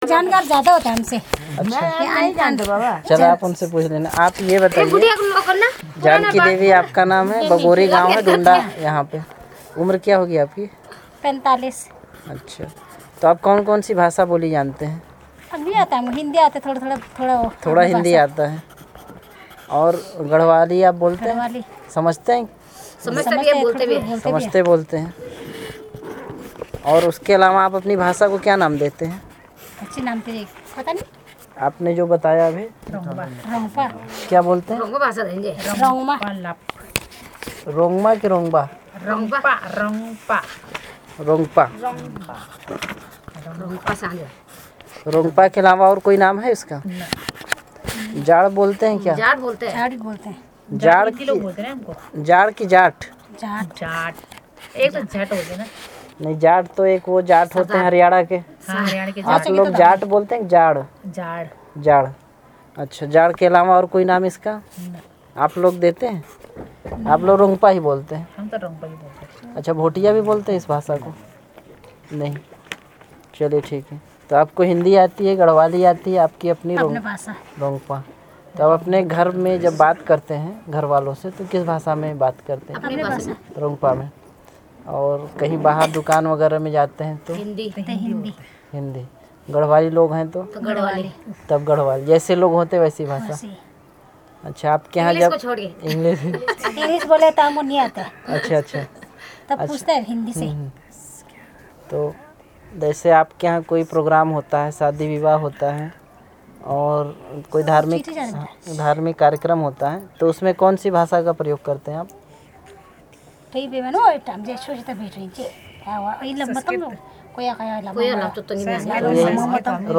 Elicitation of words about human body parts